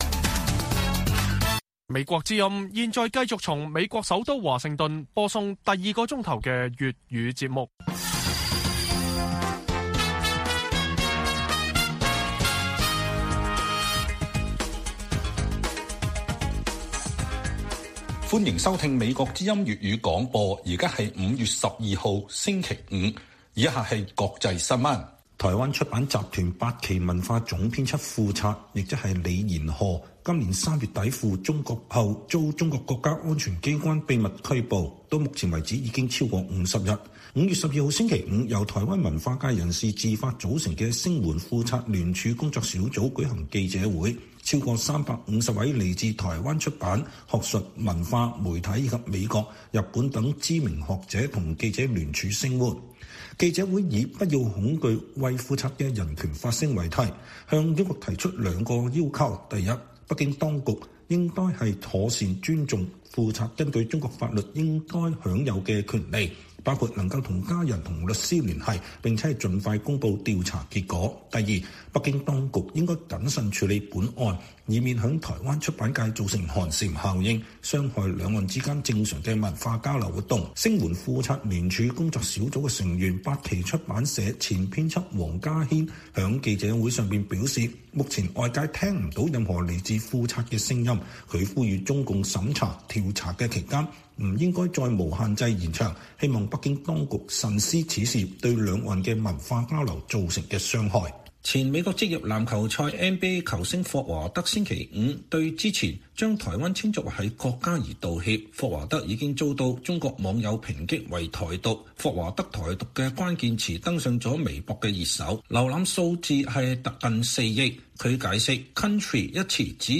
粵語新聞 晚上10-11點 : 港立法海外律師參與國安法案須特首批 矛頭指向黎智英？